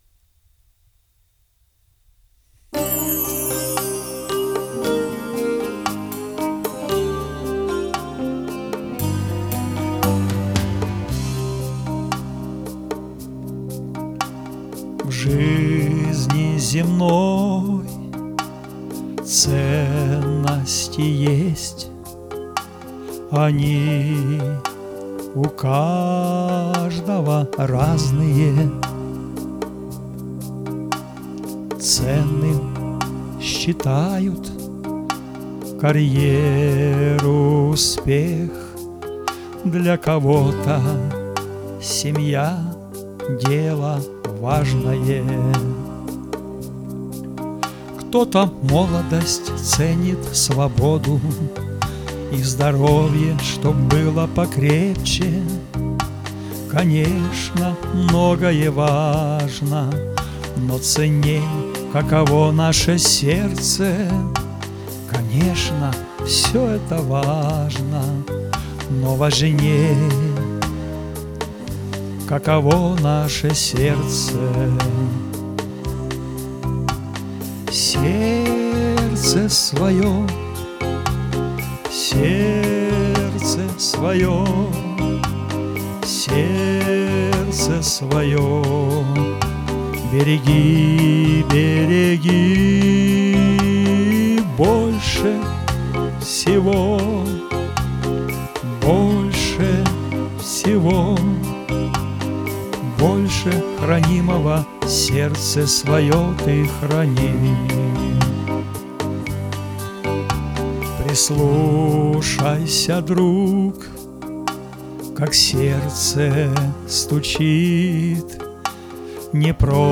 Теги: Христианские песни